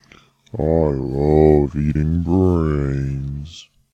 sound effects added.
eating-brains.ogg